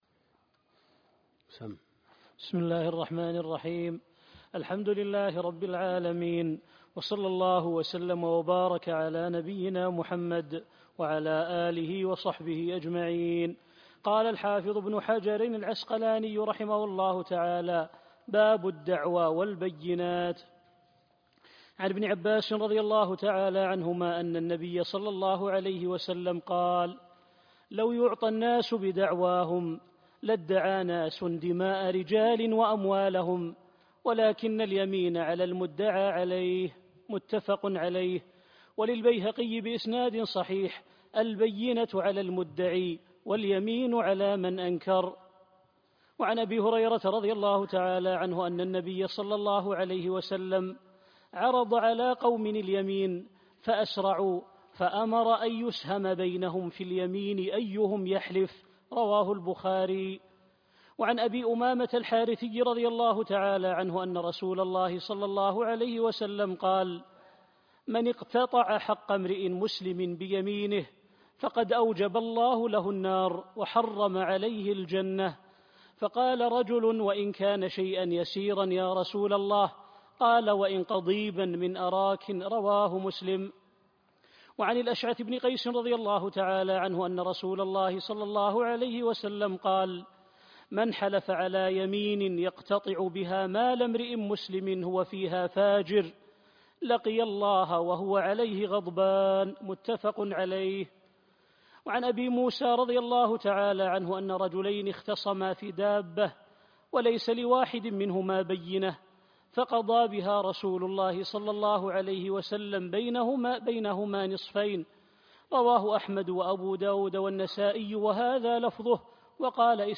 عنوان المادة الدرس (4) كتاب القضاء من بلوغ المرام تاريخ التحميل الثلاثاء 30 يناير 2024 مـ حجم المادة 40.10 ميجا بايت عدد الزيارات 175 زيارة عدد مرات الحفظ 122 مرة إستماع المادة حفظ المادة اضف تعليقك أرسل لصديق